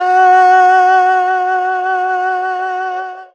52-bi13-erhu-p-f#3.wav